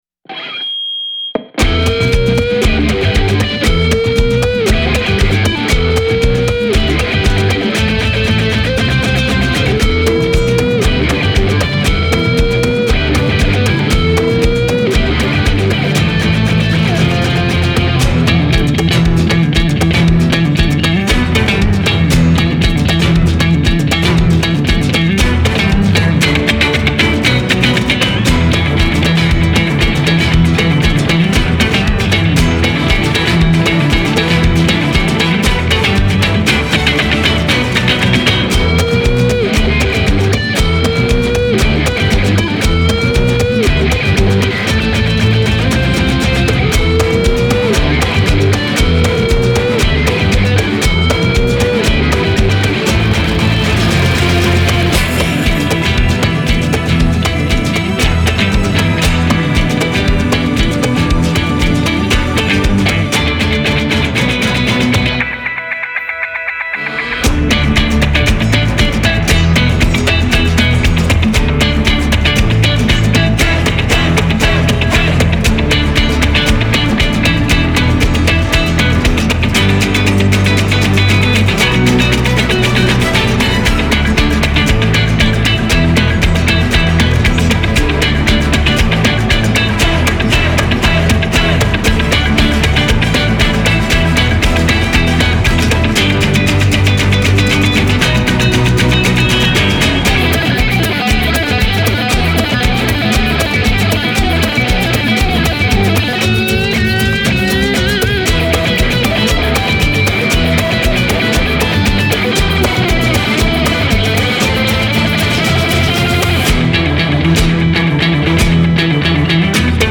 Genre : Latin